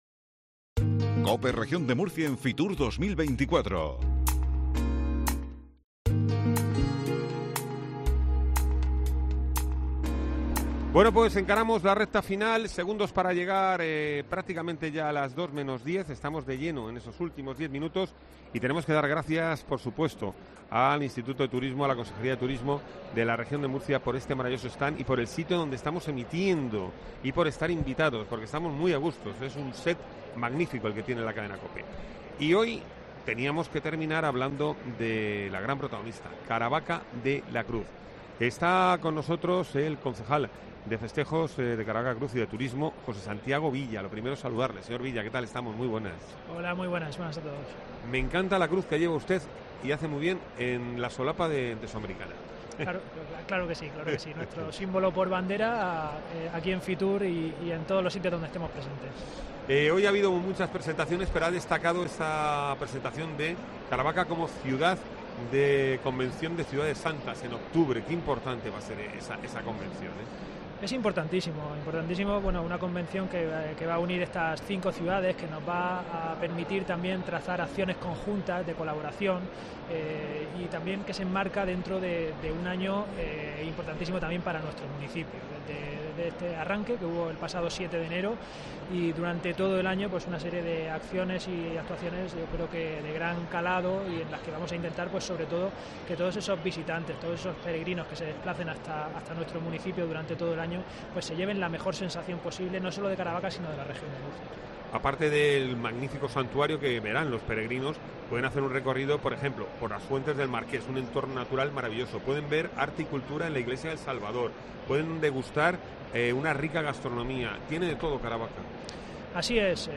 Caravaca y el turismo religioso son parte fundamental de la oferta de la Región de Murcia en Fitur 2024. Por el set de COPE ha pasado hoy Santiago Villa, el concejal de turismo de una de las cinco ciudades santas.